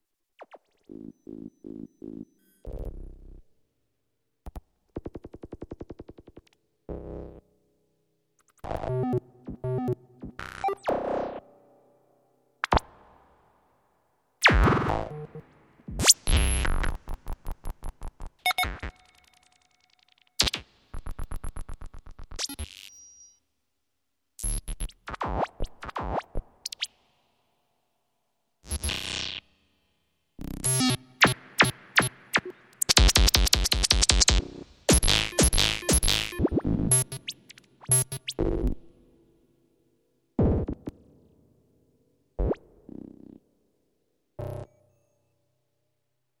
Электронные звуки кода программ